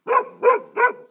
Ladridos de un perro grande